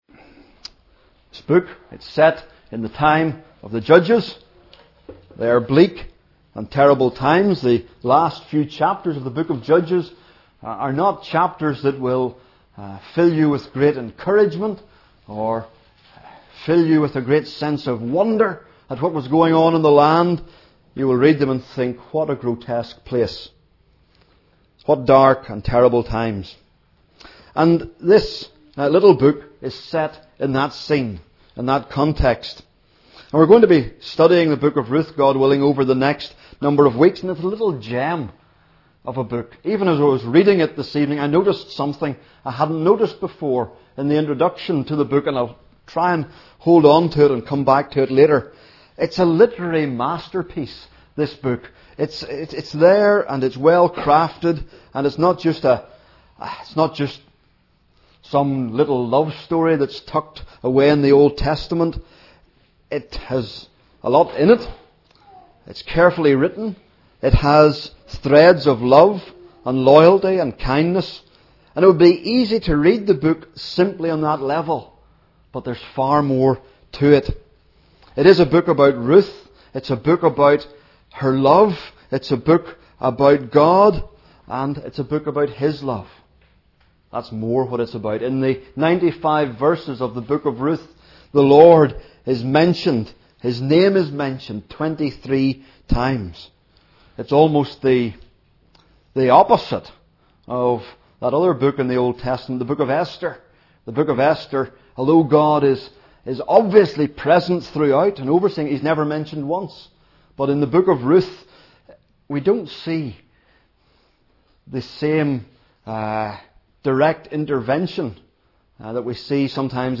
Hear the latest sermons preached in NLF, or browse the back catalogue to find something to feed your soul.